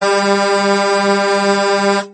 LKW Horn klingelton kostenlos
Kategorien: Soundeffekte